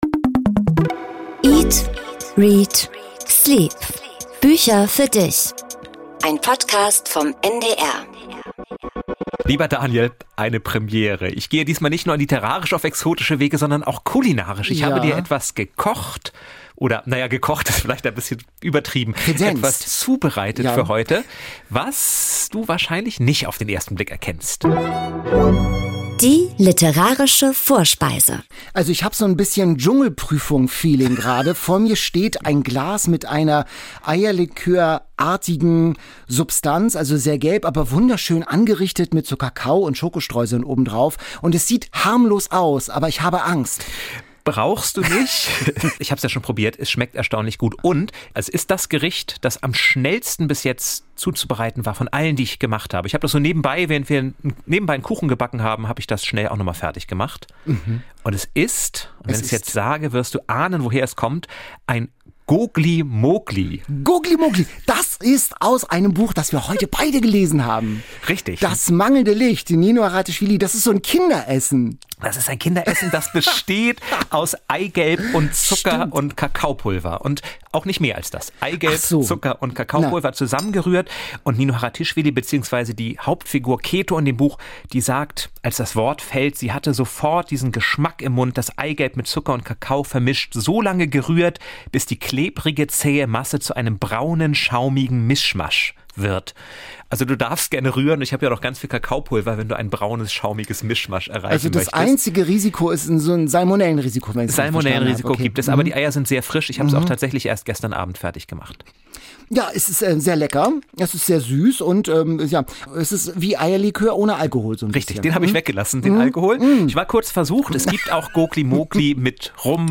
Interview mit Nino Haratischwili